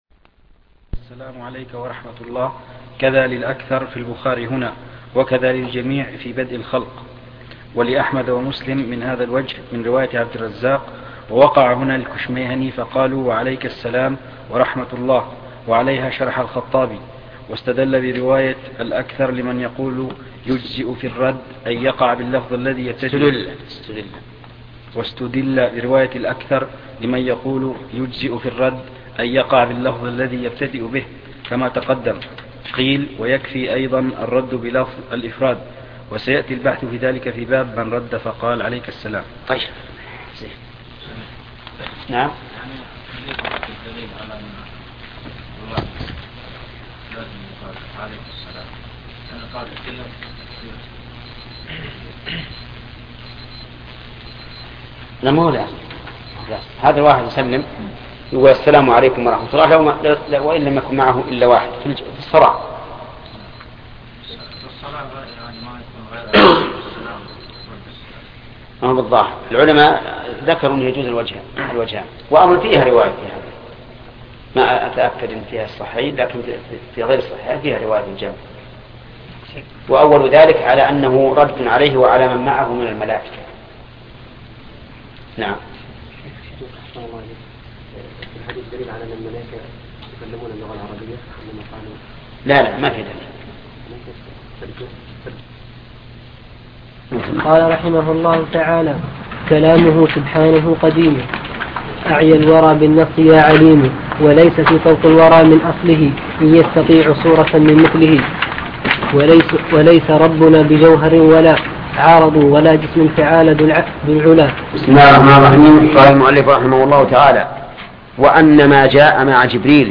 الدرة المضية في عقد أهل الفرقة المرضية (العقيدة السفارينية) شرح الشيخ محمد بن صالح العثيمين الدرس 18